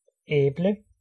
Ääntäminen
IPA: /ˈapfəl/